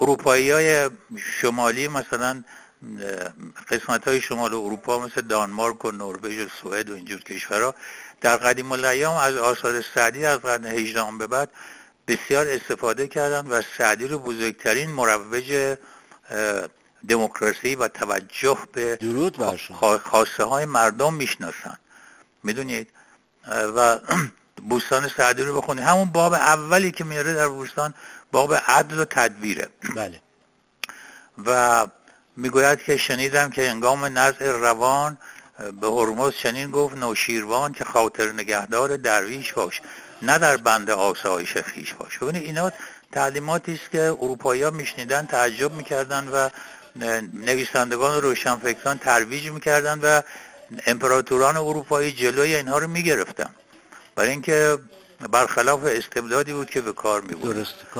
روزگار و زمانه سعدی در گفت‌وگوی ایکنا